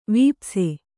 ♪ vīpse